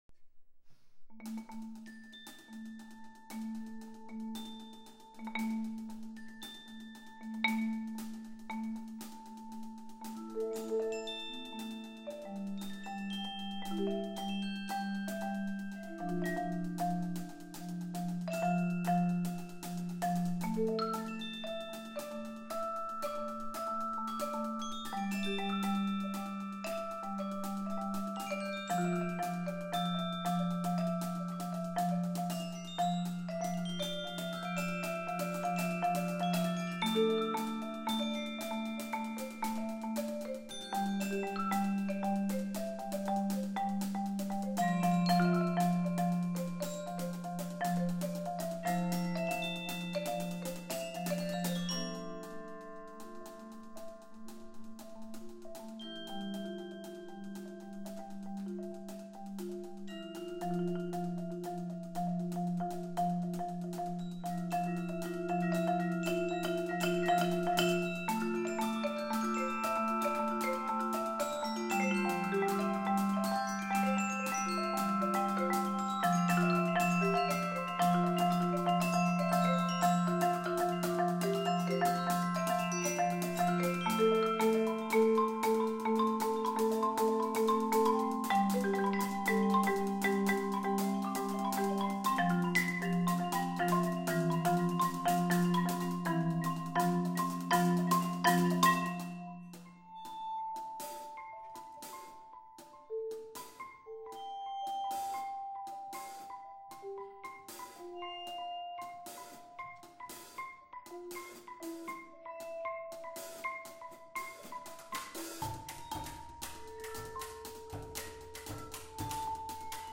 marimba with percussion trio